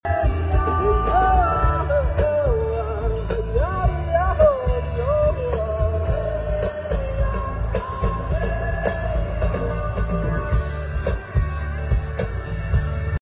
CVR 3-way 10 inch line array W-310 & double 18 inch subwoofer for tonight's concert